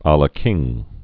lə kĭng, ălə)